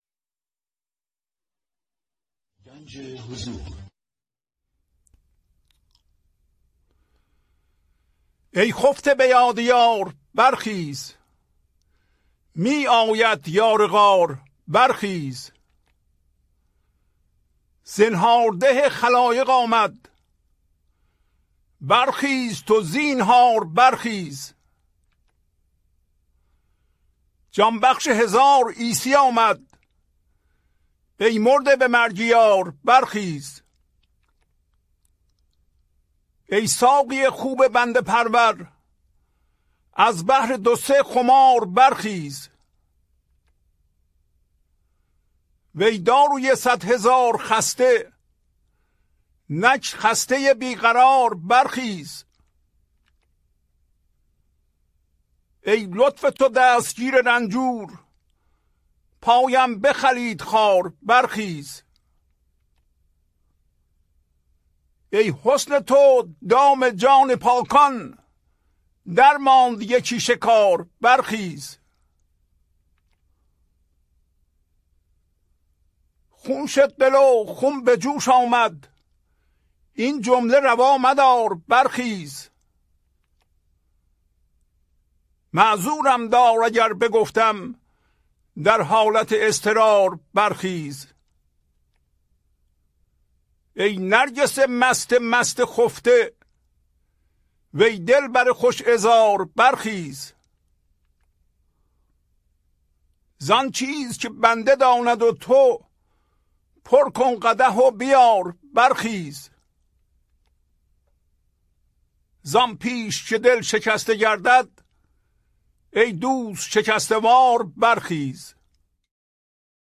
خوانش تمام ابیات این برنامه - فایل صوتی
1041-Poems-Voice.mp3